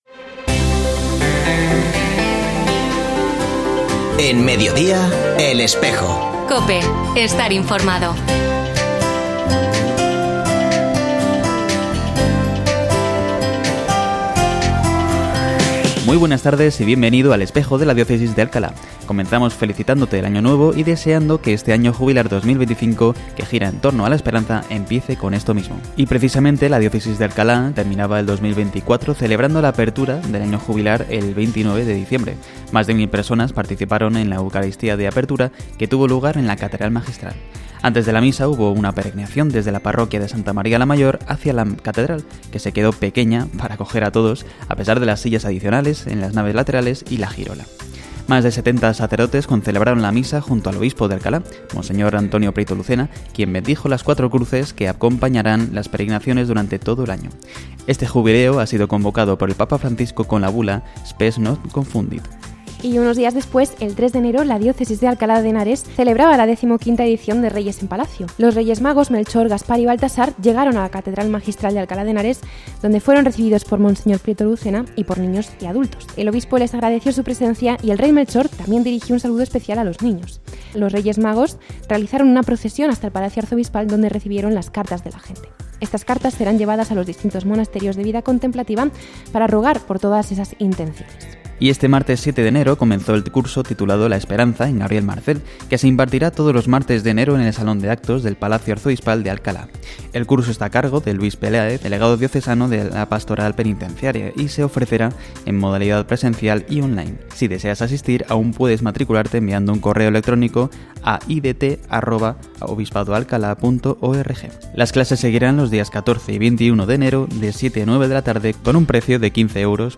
Se ha vuelto a emitir hoy, 10 de enero de 2025, en la emisora de radio COPE. Este espacio de información religiosa de nuestra diócesis puede escucharse en la frecuencia 92.0 FM, todos los viernes de 13.33 a 14 horas.